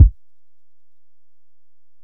Kick (19).wav